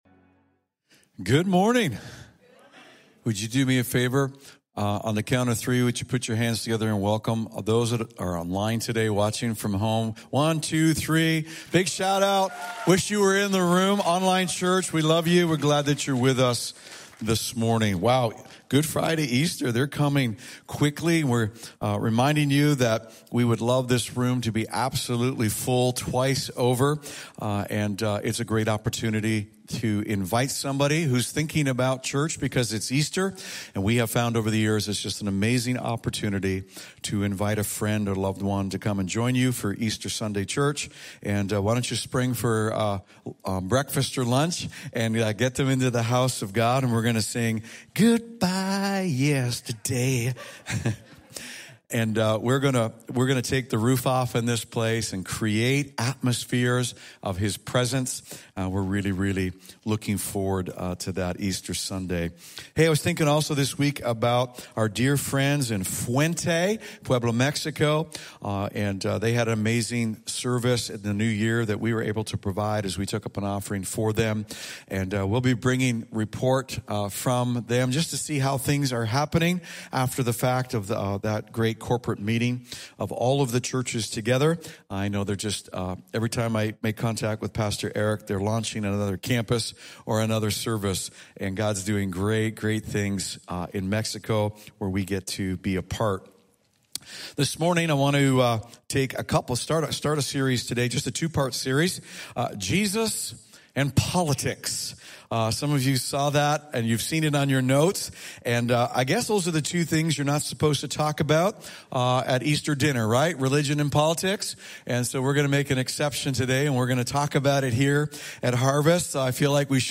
The latest messages from Harvest's weekend service in Cornwall Ontario